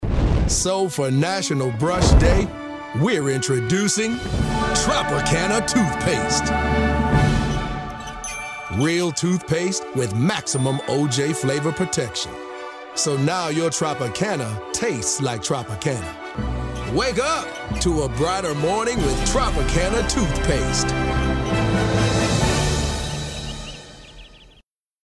slightly raspy, authoritative narration, upbeat urban commercial, cool, energetic, & fun promos
announcer, confident, friendly, humorous, raspy